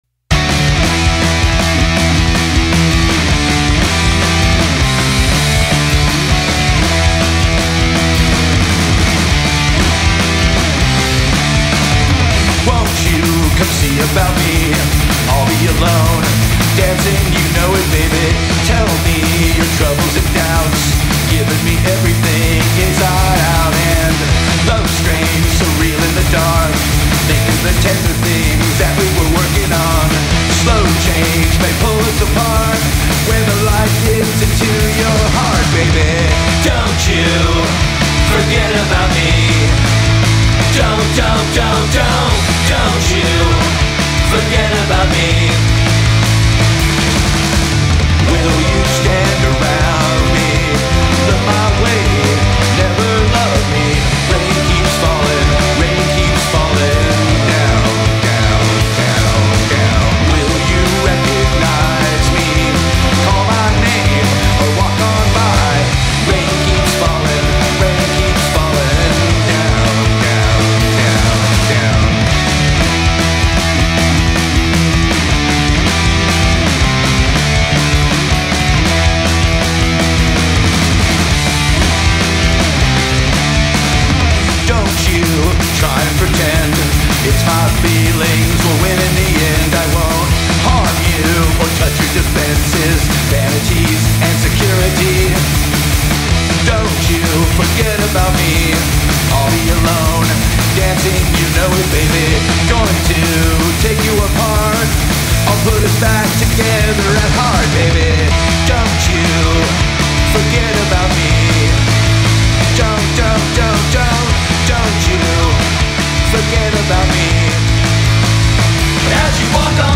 Punked-out versions of classics.